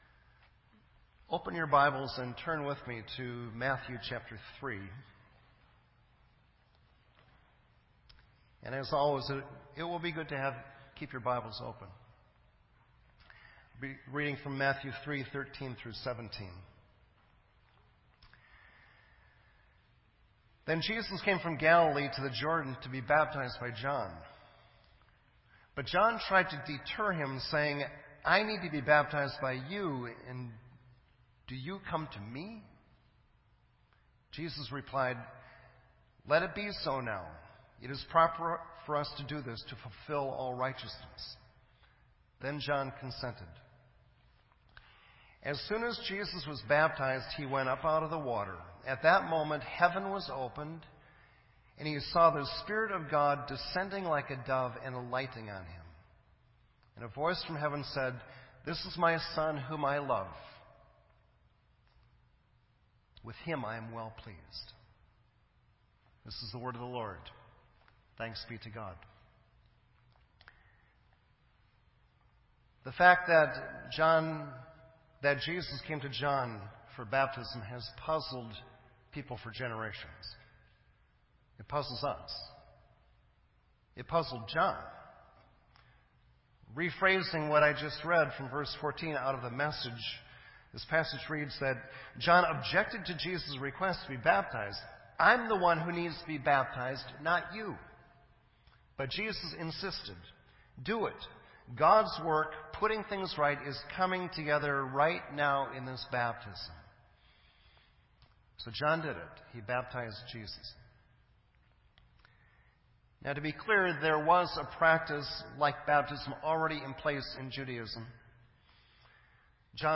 This entry was posted in Sermon Audio on January 16